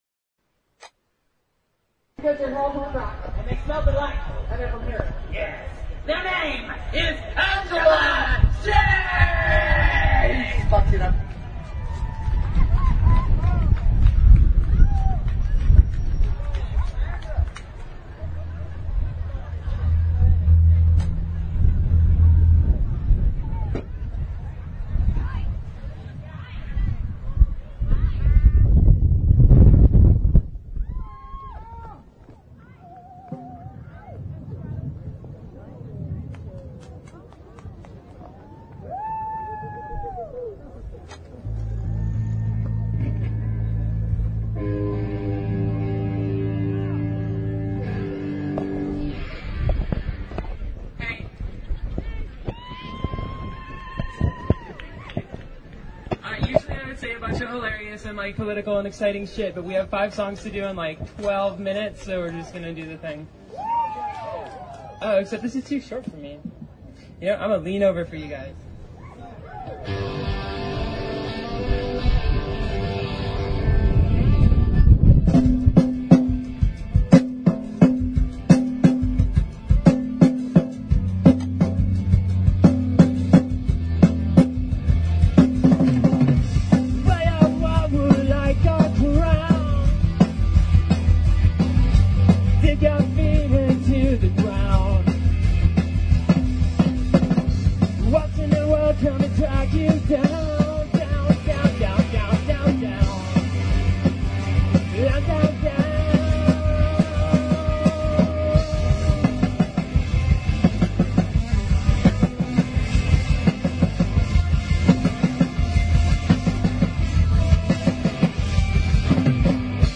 hear audio from two TransMarch bands; punk trio
short songs, excellent sound
plays a high-spirited accoustic set